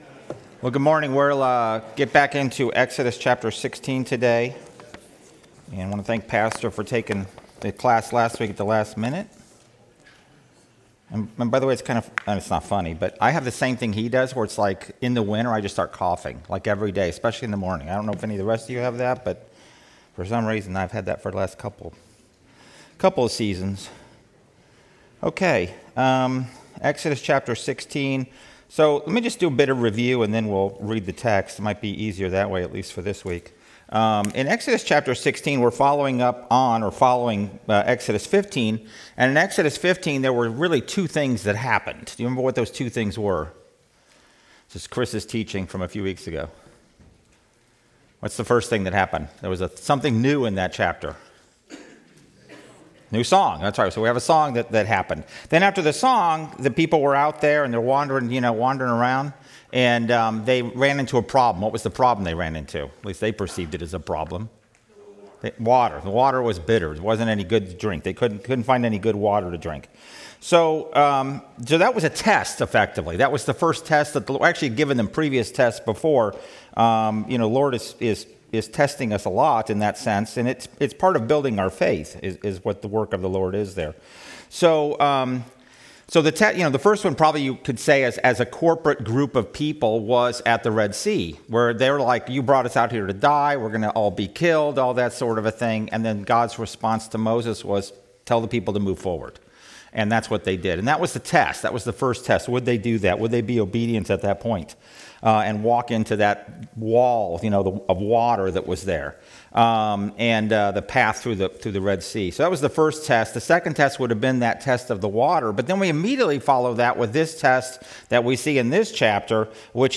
Passage: Exodus 16 Service Type: Sunday School